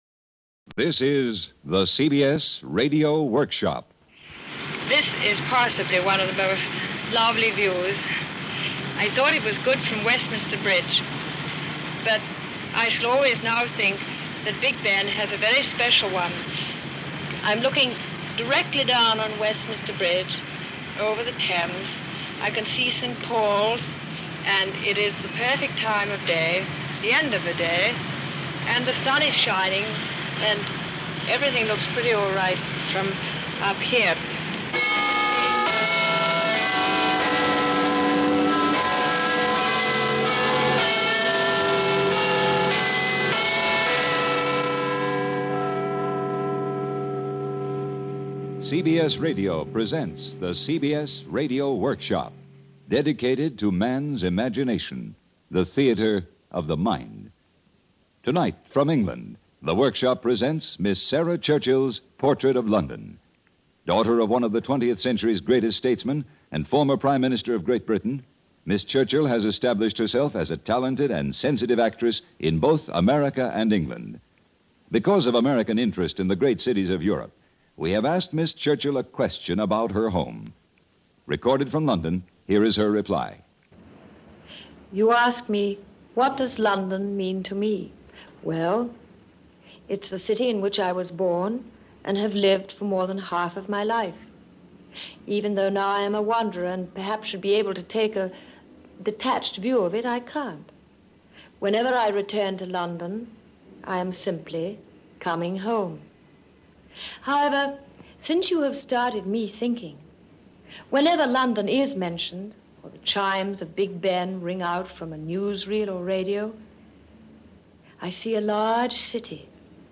CBS Radio Workshop with host and narrator William Conrad